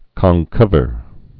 (kŏn-kŭvər, -khr, kŏnər)